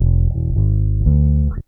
BASS 23.wav